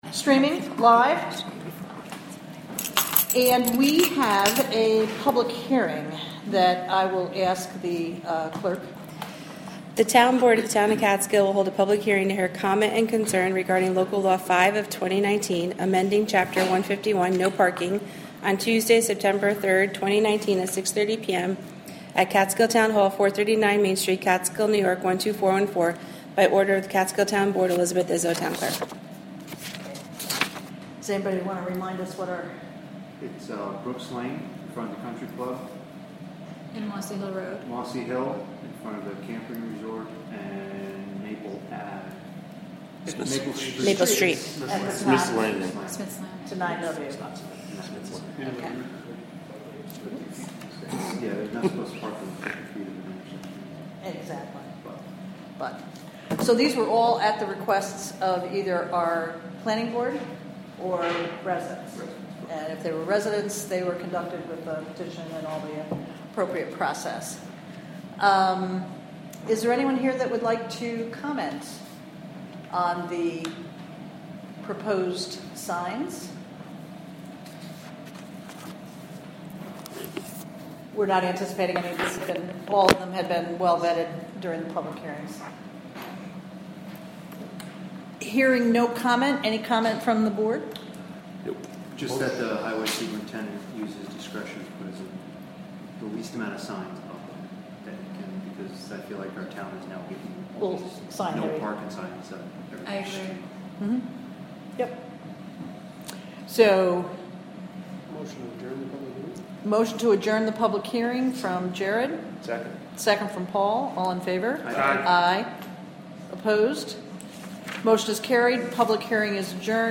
Recorded from a live webstream created by the Town of Catskill through the Wave Farm Radio app. Monthly Town Board meeting with a Public Hearing about Local Law 5 of 2019 amending Chapter 151, "No Parking".